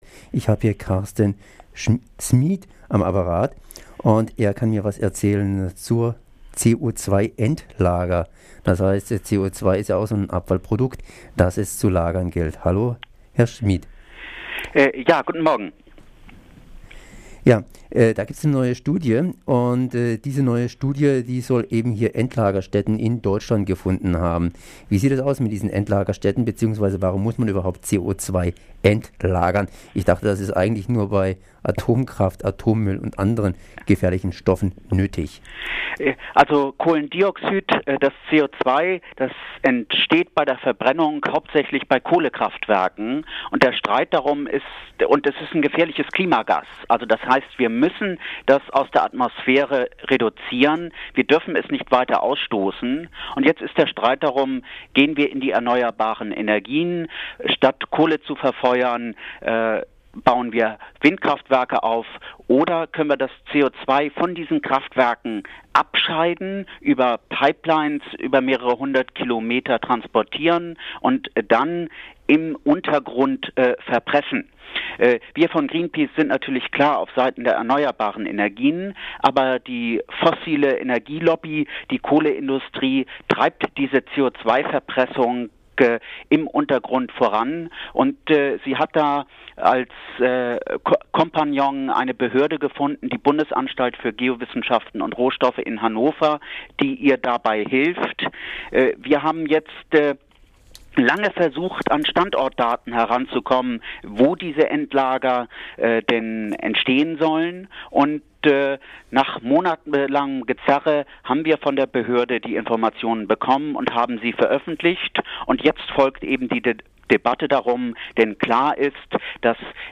Ein Gespräch